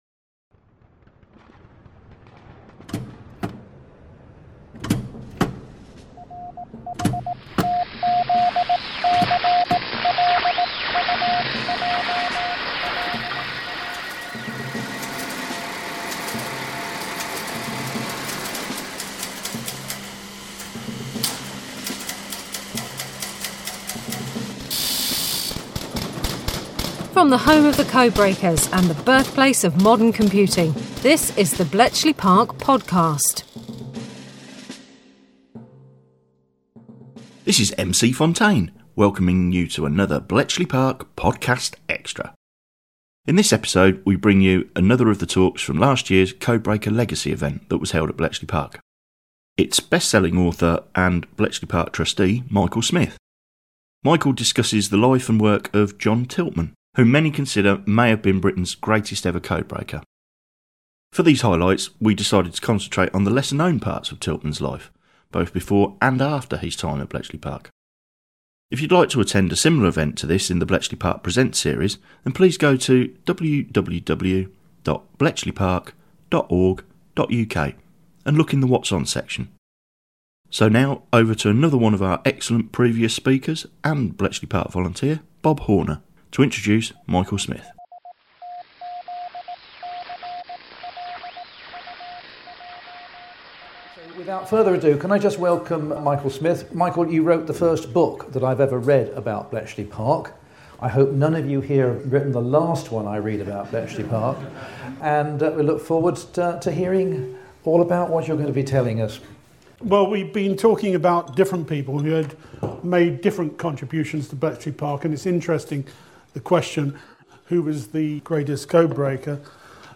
This talk was recorded at last year’s sell-out day of talks, Codebreakers’ Legacy.